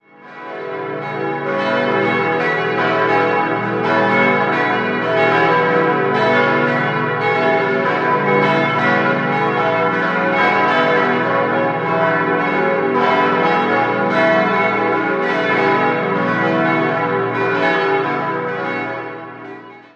5-stimmiges Geläute: h°-cis'-e'-fis'-gis'
Trier_Gangolf.mp3